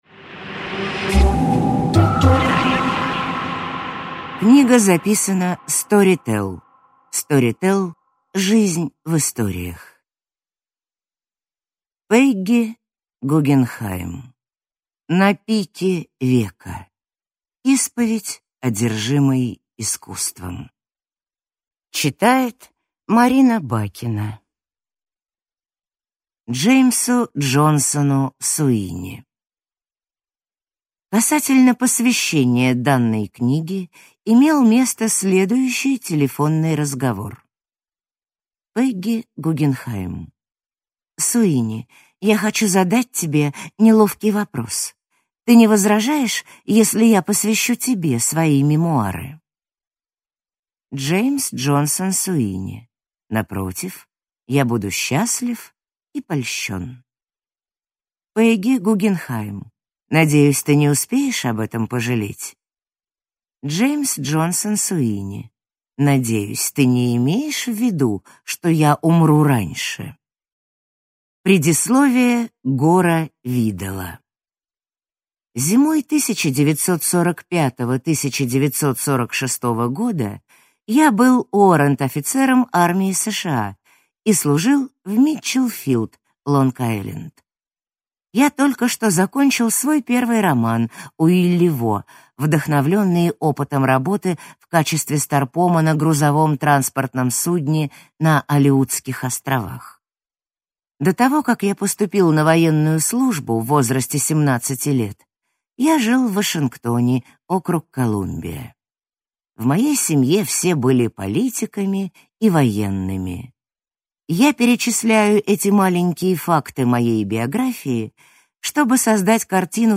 Аудиокнига На пике века. Исповедь одержимой искусством | Библиотека аудиокниг